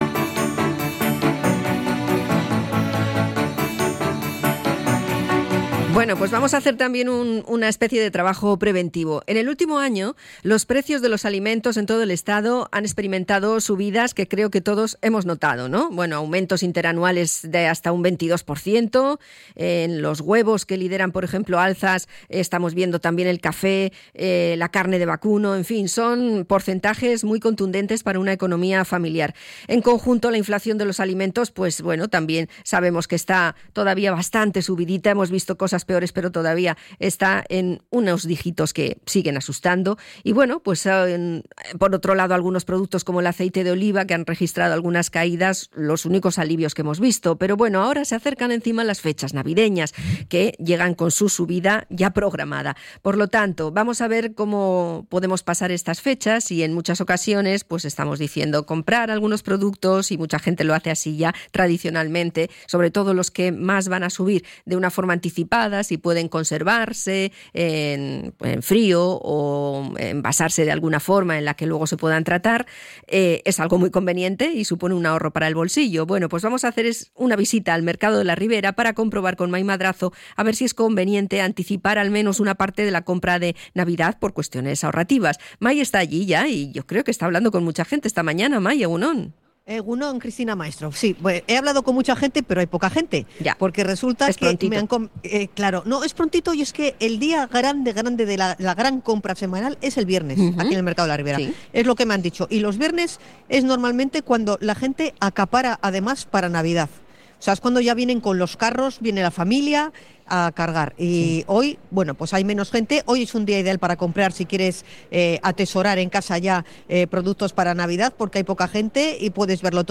Hablamos con comerciantes y clientes en el Mercado de La Ribera
REPORTAJE-COMPRAS-PRENAVIDENAS.mp3